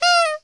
FNAF Honk
fnaf-honk.mp3